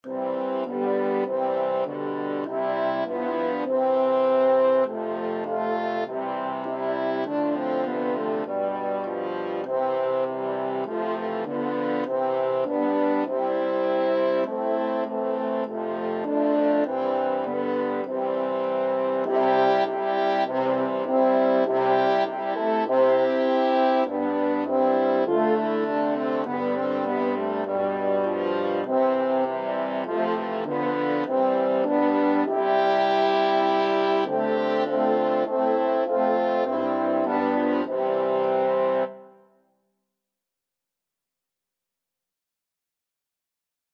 Andante
4/4 (View more 4/4 Music)